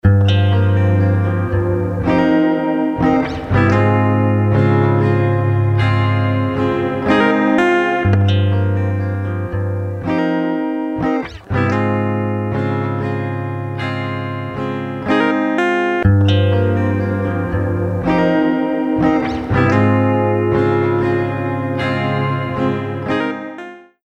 MicroPitchは、サウンドを自然に太くする高解像度ピッチシフトと、ドラマチックなスラップバックを生み出すディレイを融合したプラグインです。
MicroPitch | Electric Guitar | Preset: Ethereal Piano Recital
MicroPitch-Eventide-Guitar-Ethereal-Piano-Recital.mp3